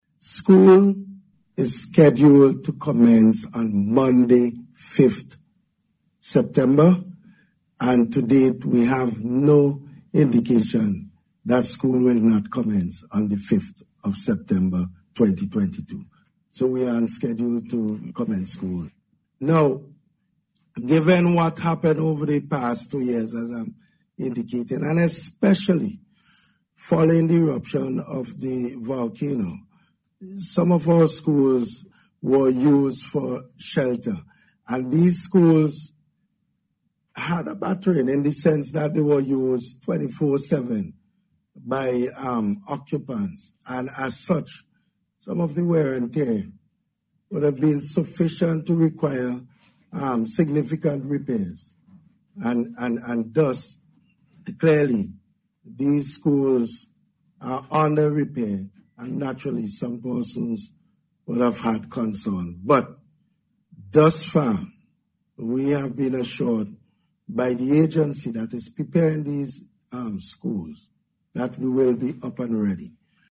Minister of Education, Curtis King said on Radio yesterday that all stakeholders are working to ensure that everything is in place for the reopening of schools.